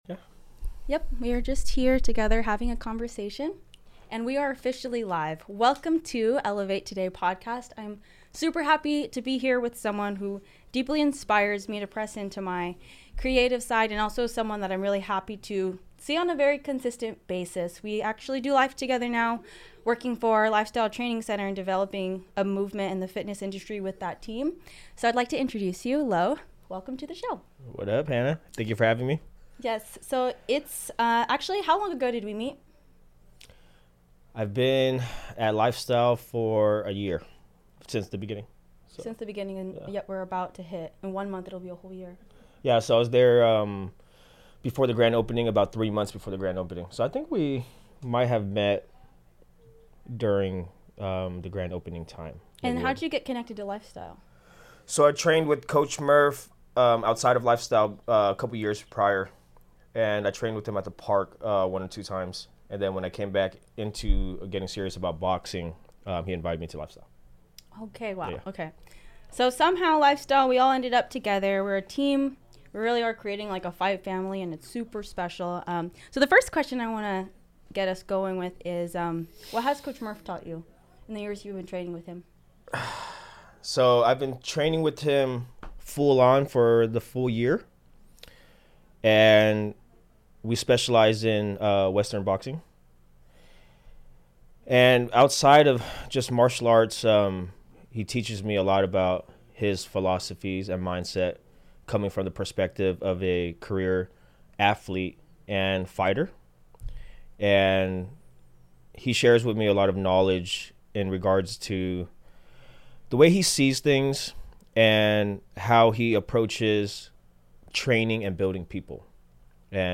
Tune in for an inspiring conversation about self-awareness, purpose, and the transformative power of community.